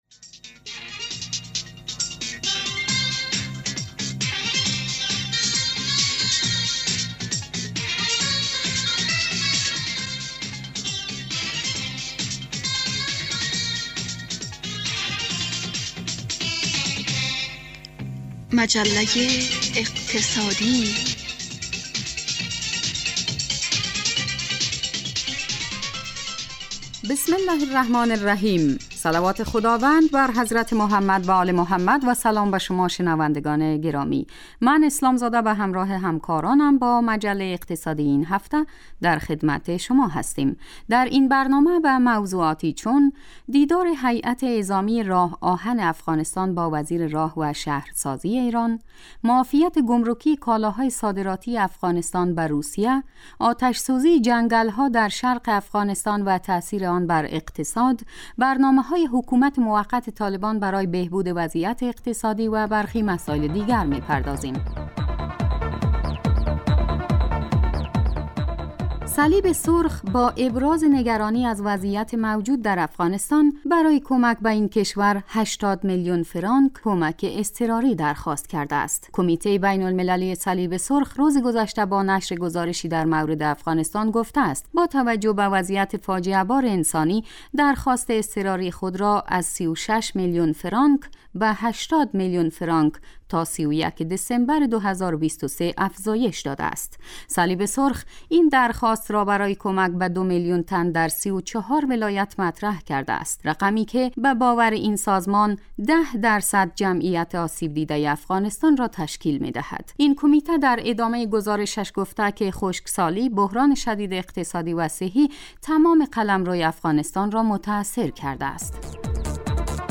برنامه مجله اقتصادی به مدت 35 دقیقه روز جمعه در ساعت 15:00 بعد از ظهر (به وقت افغانستان) پخش می شود. این برنامه به بررسی رویدادهای اقتصادی مربوط به افغانستان می پردازد.